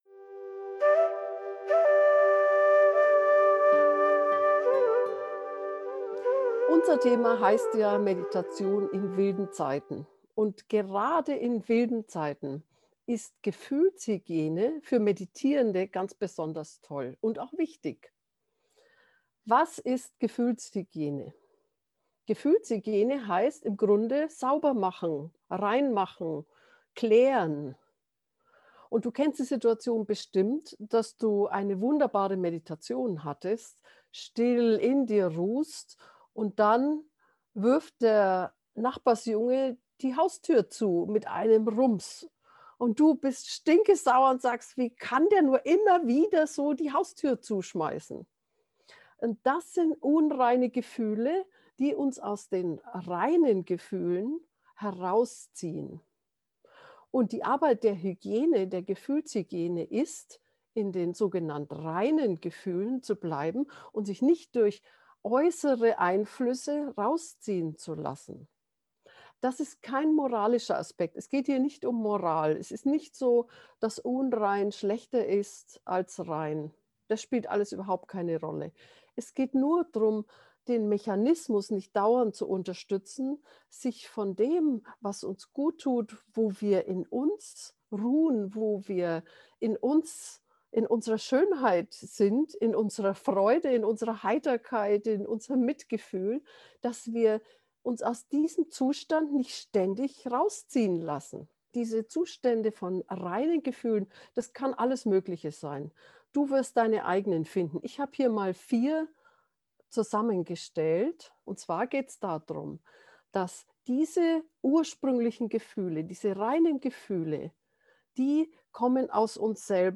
gefuehlshygiene-gefuehrte-meditation